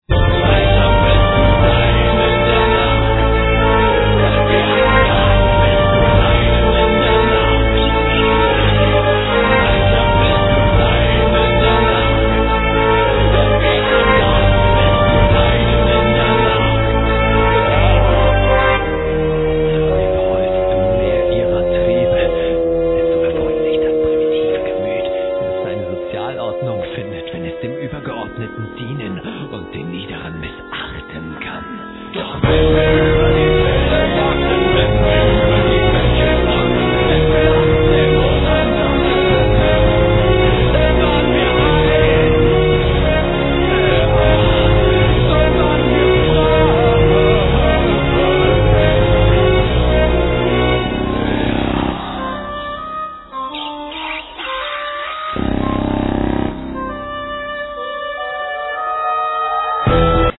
Vocals, Programming
Vocals